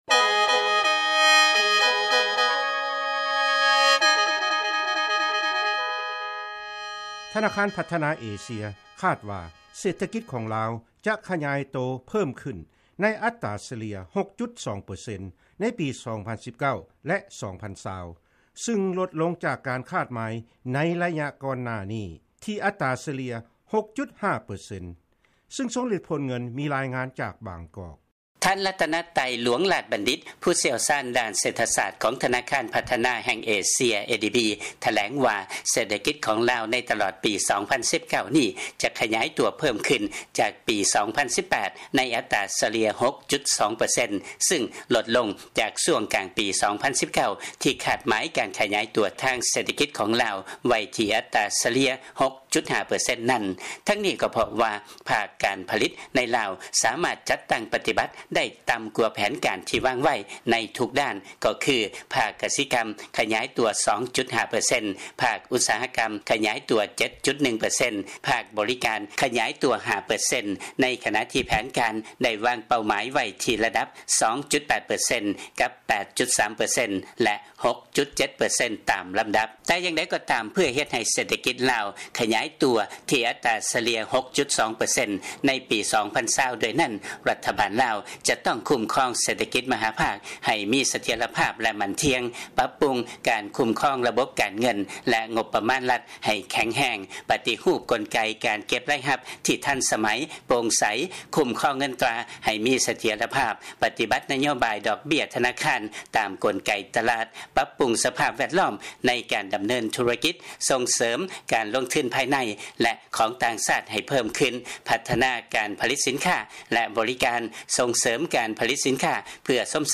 ຟັງລາຍງານ ADB ຄາດວ່າ ເສດຖະກິດຂອງ ລາວ ຈະຂະຫຍາຍຕົວ ໃນອັດຕາສະເລ່ຍ 6.2 ເປີເຊັນໃນປີ 2019 ແລະ 2020